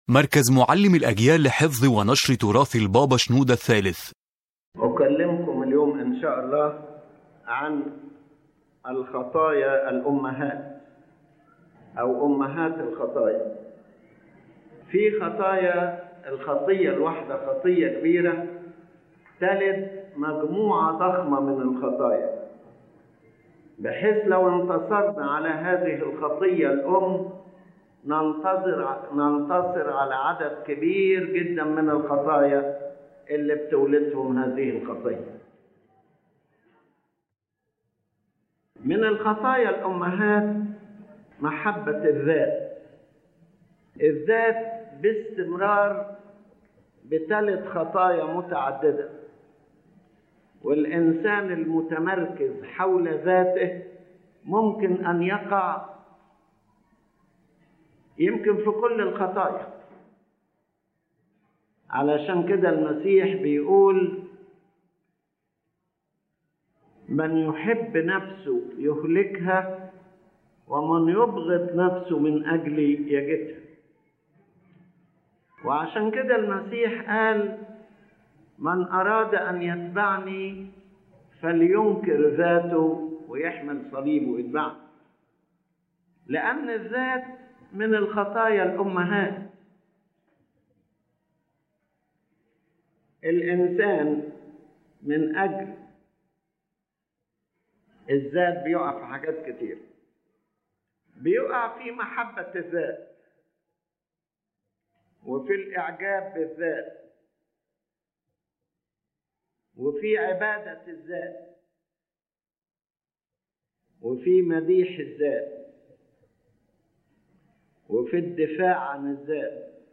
The lecture discusses the concept of “mother sins,” which are the main sins that give birth to many other sins. If a person overcomes these root sins, they can overcome a large number of related sins.